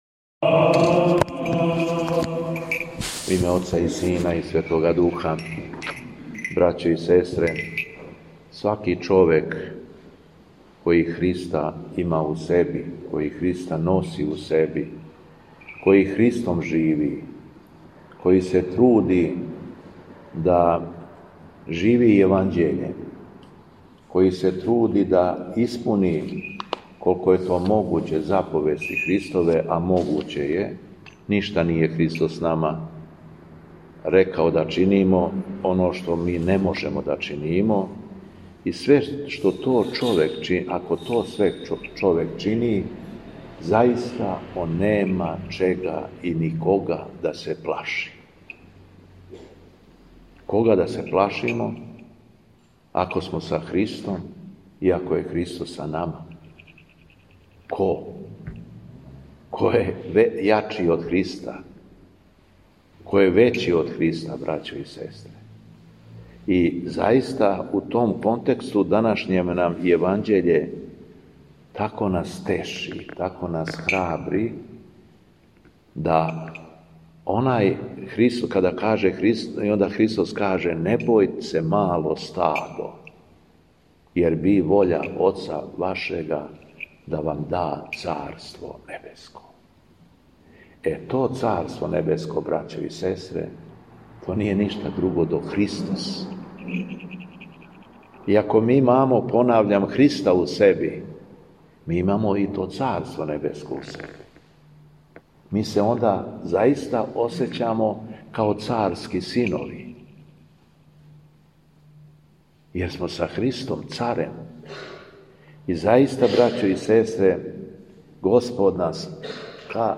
Беседа Његовог Високопреосвештенства Митрополита шумадијског г. Јована
Митрополит се обратио сакупљеном народу након прочитаног Јеванђеља: